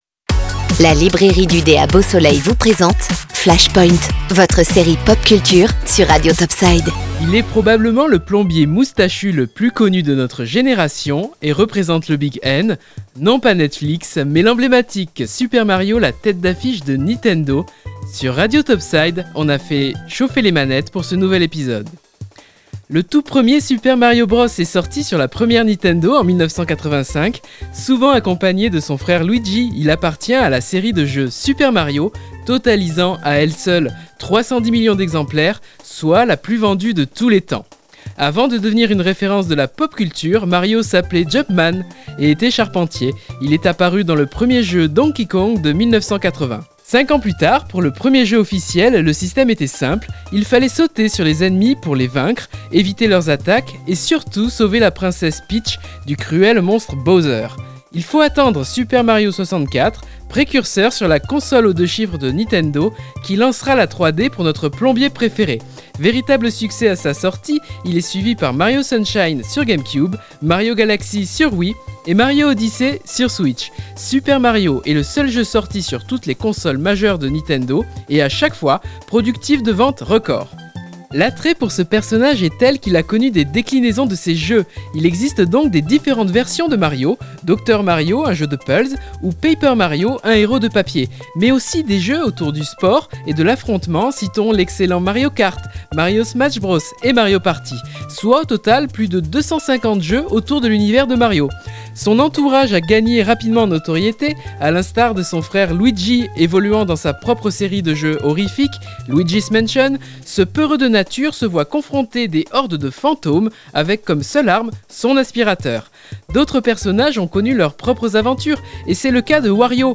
Flash Point chronique consacrée à la pop culture (bandes dessinées de type comics/mangas, films, séries, jeux vidéo des années 80 à aujourd’hui…) sur Radio Top Side tous les vendredis entre 18h et 19h.